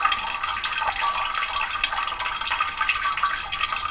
water_running_dripping_wee_loop_01.wav